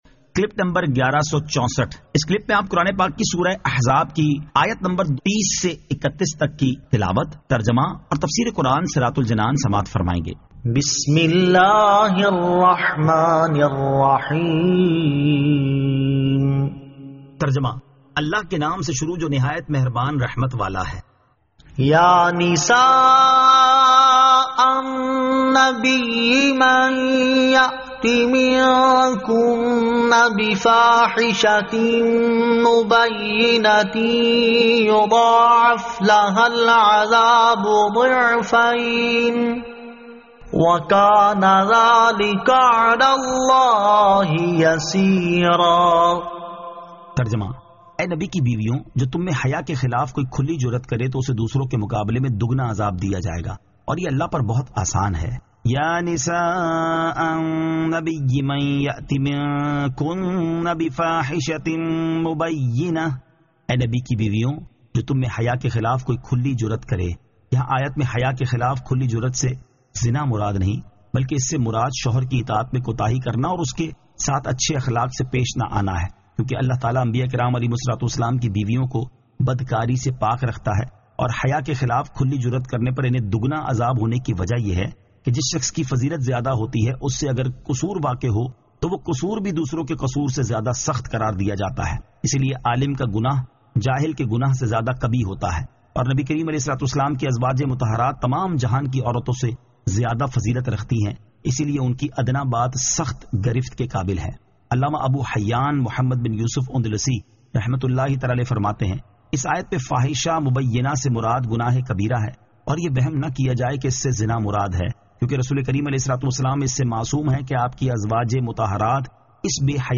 Surah Al-Ahzab 30 To 31 Tilawat , Tarjama , Tafseer